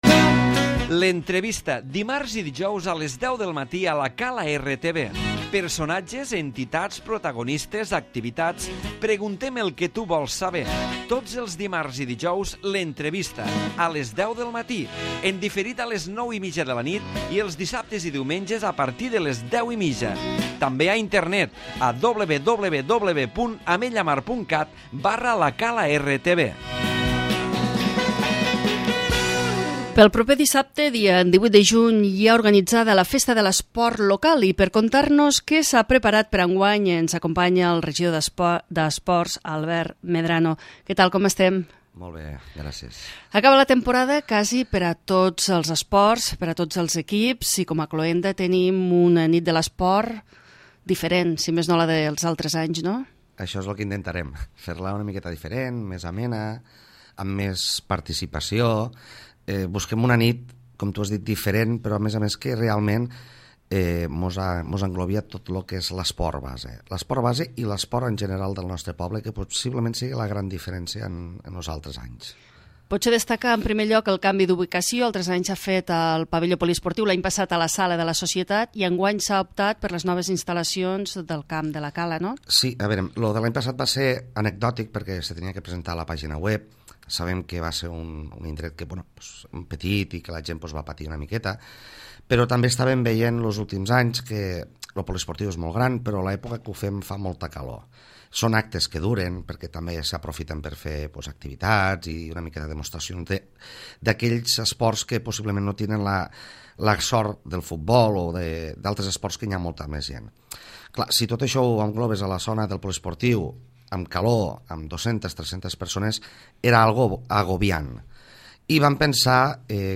L'Entrevista
Nit de l'esport ''la Cala'' amb sopar a l'aire lliure, activitats esportives, lliurament de premis i festa Dj a la zona esportiva. De tot plegat n'hem parlat avui a la nostra entrevista amb el regidor d'esports Albert Medrano.